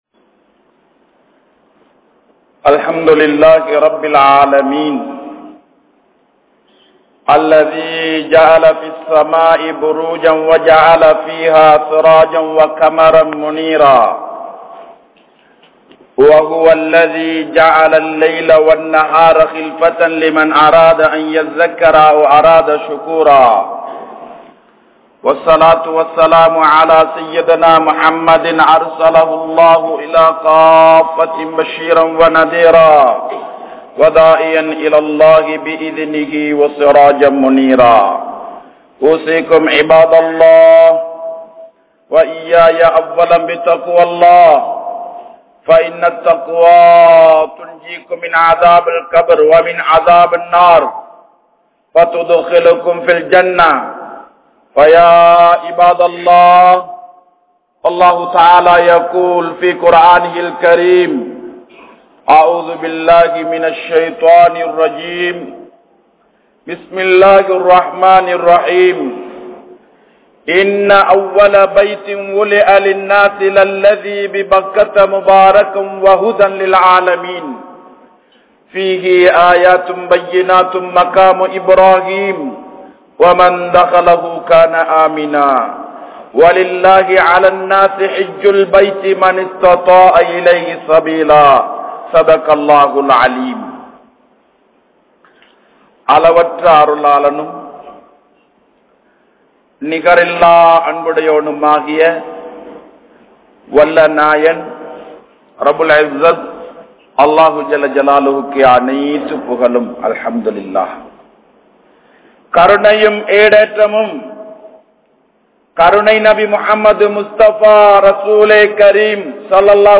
kaubavin Sirappuhal(கஃபாவின் சிறப்புகள்) | Audio Bayans | All Ceylon Muslim Youth Community | Addalaichenai